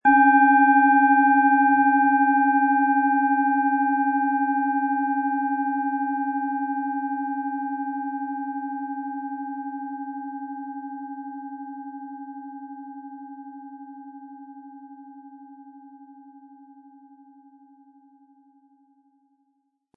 OM Ton
Von Hand hergestellte Klangschale mit dem Planetenton OM-Ton.
MaterialBronze